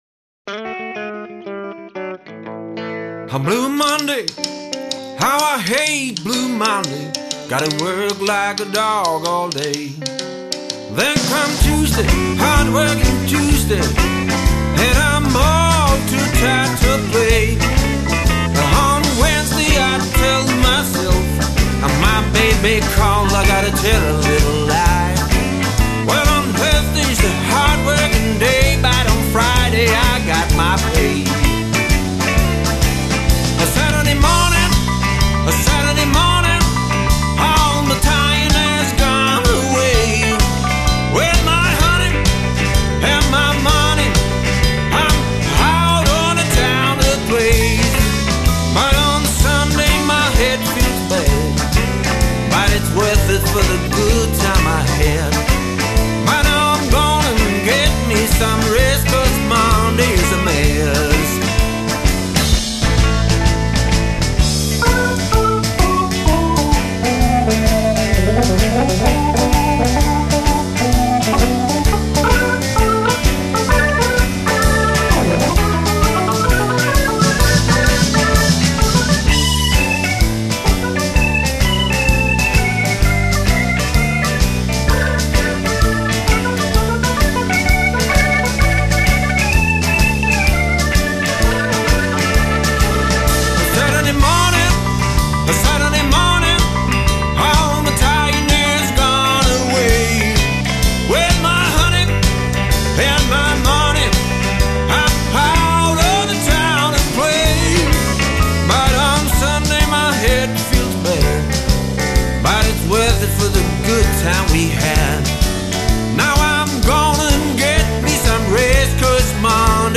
100% live med äkta spelglädje.